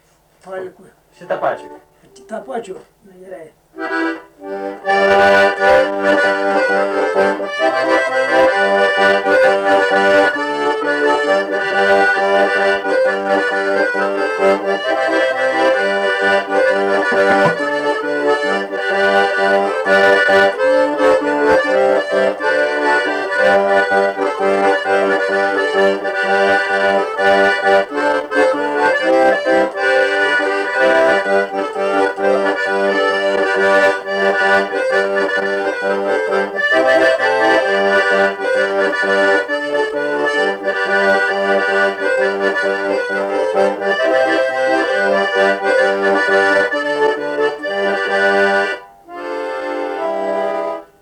instrumentinis
smuikas